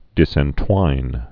(dĭsĕn-twīn)